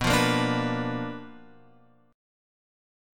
B Minor Major 7th Flat 5th